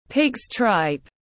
Lòng lợn tiếng anh gọi là pig’s tripe, phiên âm tiếng anh đọc là /pɪgz traɪp/
Pig’s tripe /pɪgz traɪp/